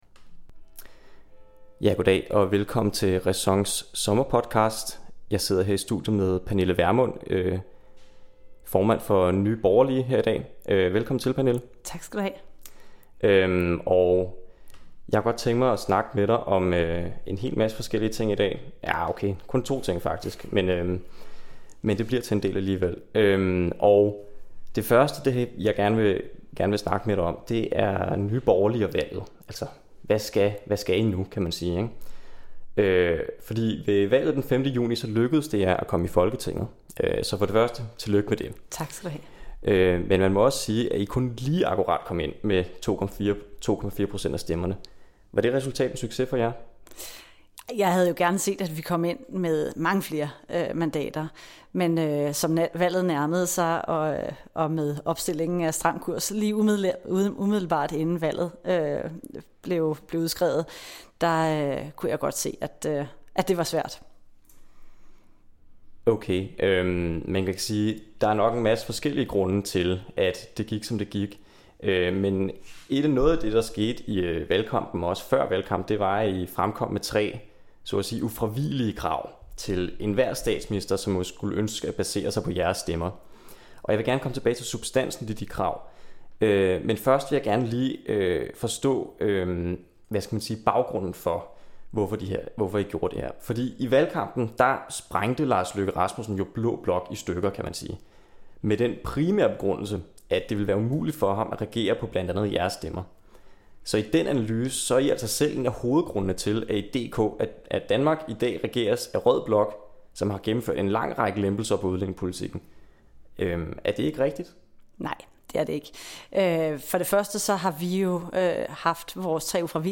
interview-med-pernille-vermund-konv.mp3